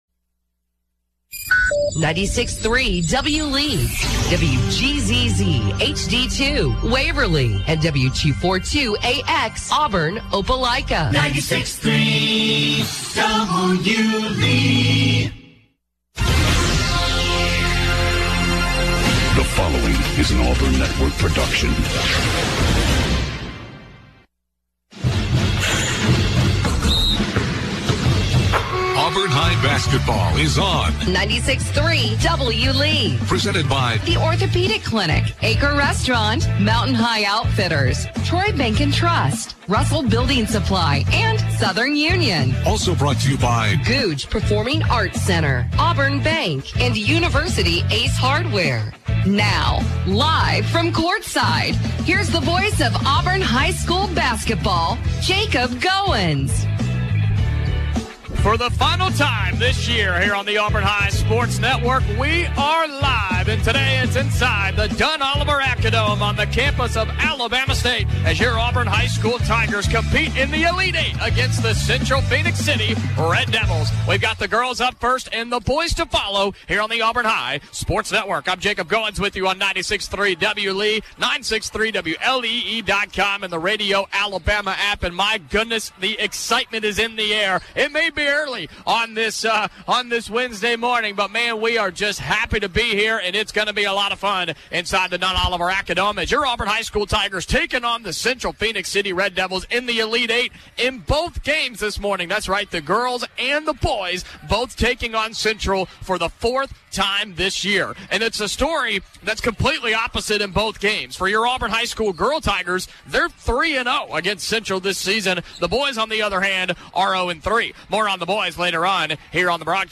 calls Auburn High's game versus the Central Phenix City Red Devils in the State Tournament Elite Eight